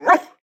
Minecraft Version Minecraft Version latest Latest Release | Latest Snapshot latest / assets / minecraft / sounds / mob / wolf / cute / bark3.ogg Compare With Compare With Latest Release | Latest Snapshot
bark3.ogg